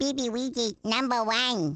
One of Baby Luigi's voice clips from the Awards Ceremony in Mario Kart: Double Dash!!